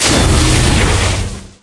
Media:jessie_turret_01.wav 部署音效 turret 炮台放置音效
Jessie_turret_01.wav